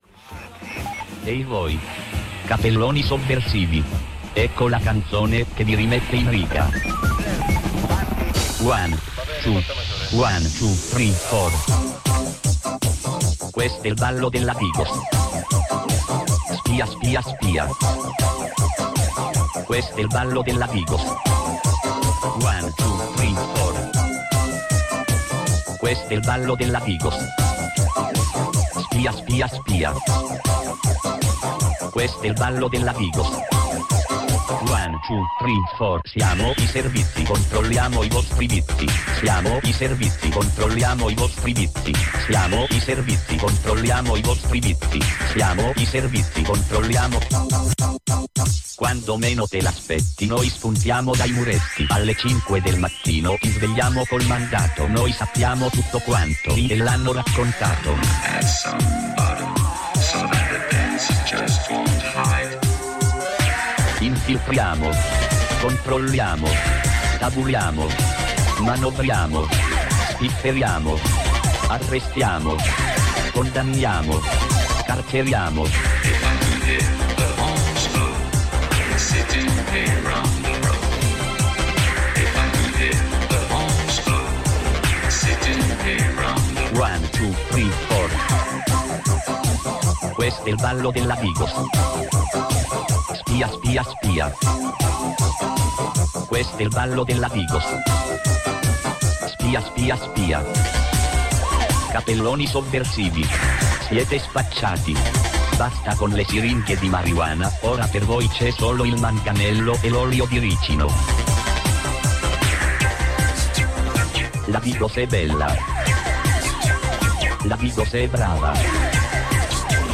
Selezione musicale